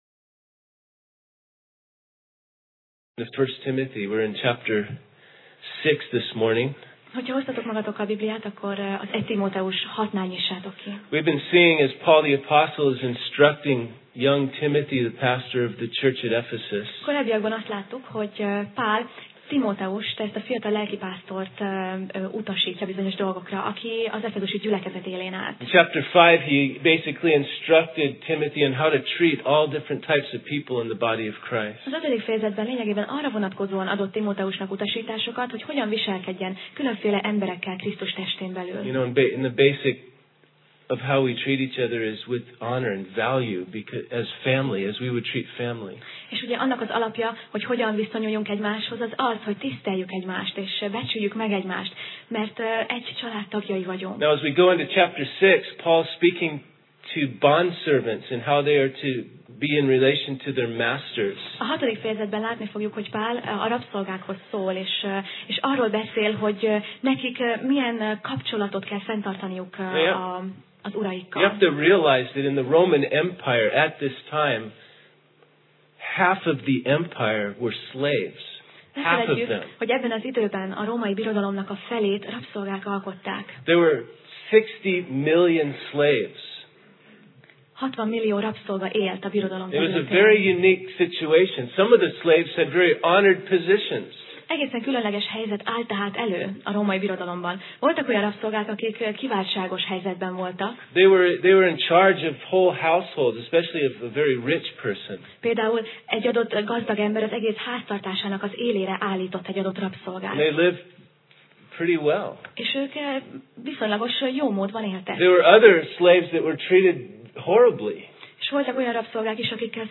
Passage: 1Timóteus (1Timothy) 6:1-14 Alkalom: Vasárnap Reggel